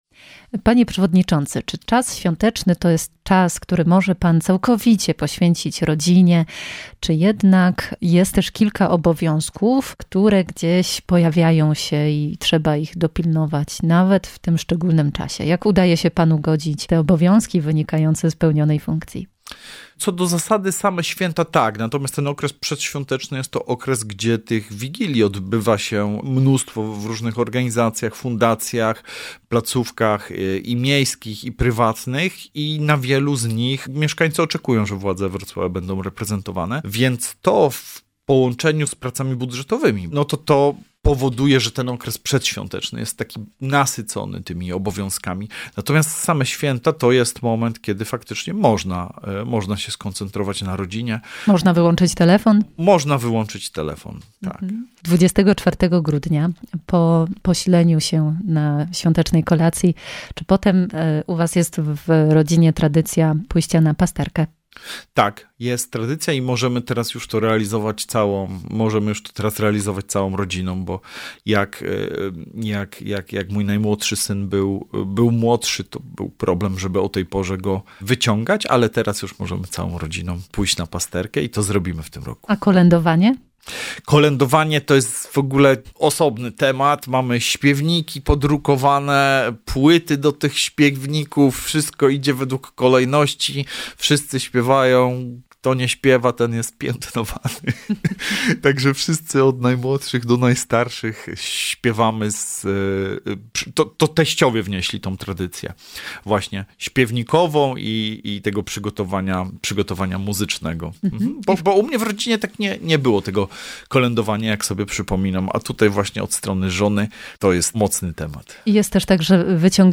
Przystrojona z dziećmi choinka, zbierane przez lata dekoracje, szopka bożonarodzeniowa przywieziona z Palermo czy w końcu czas krzątania się w kuchni przed Wigilią i spotkania przy wspólnym stole. Przewodniczący Rady Miejskiej Wrocławia, Sergiusz Kmiecik, dzieli się tym, jak spędza święta Bożego Narodzenia z rodziną.